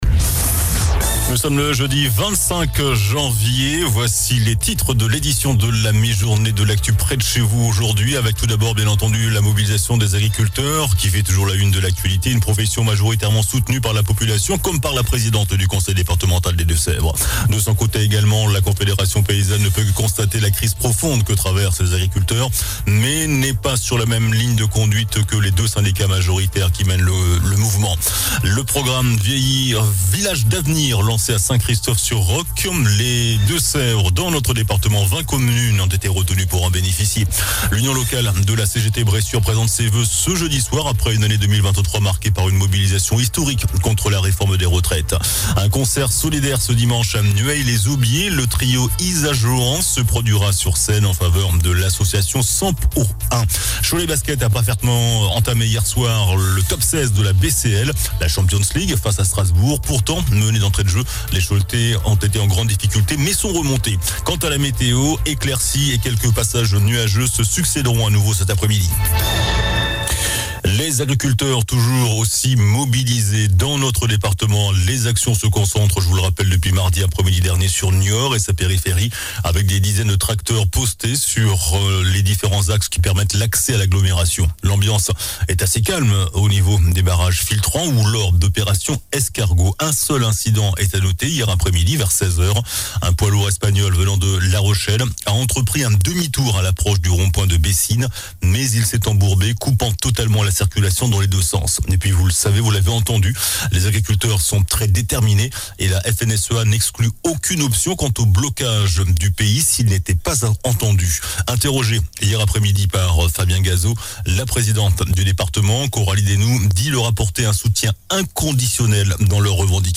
JOURNAL DU JEUDI 25 JANVIER ( MIDI )